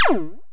laser01.wav